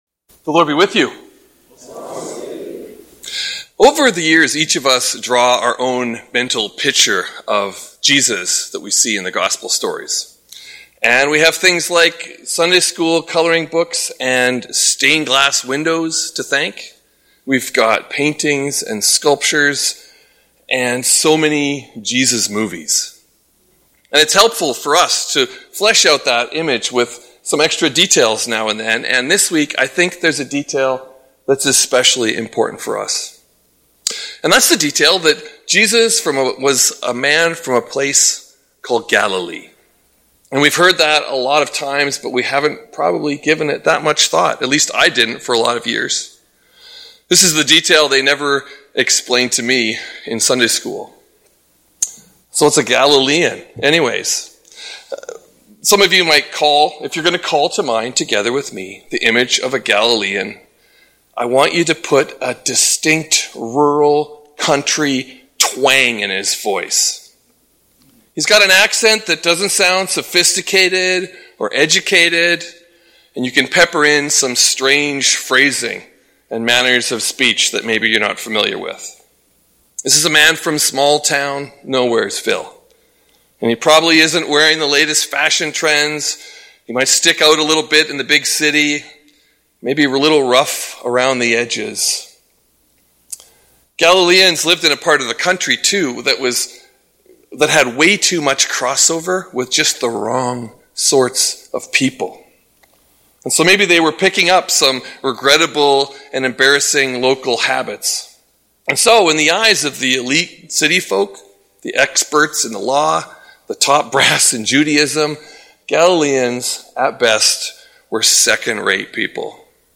Sermon Podcast for First Baptist Church of Edmonton, Alberta, Canada